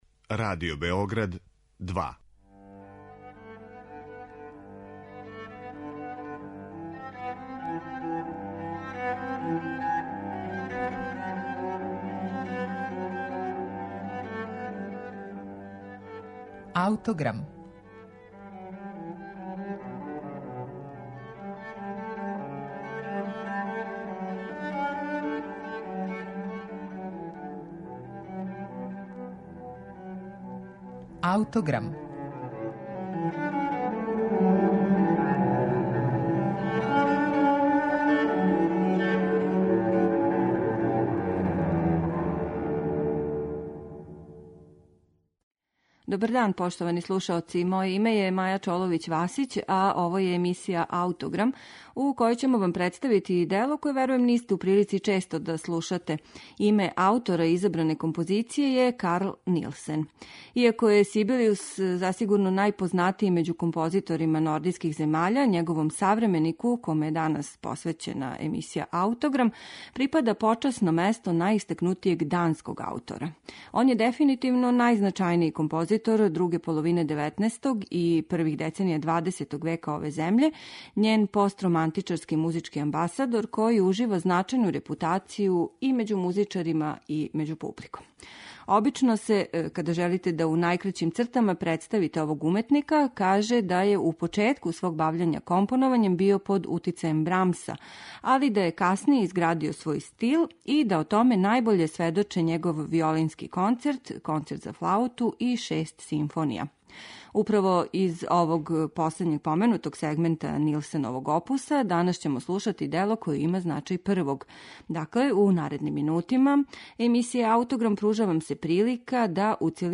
Симфонију ћете данас слушати у извођењу оркестра Алстер, којим диригује Јанош Фирст.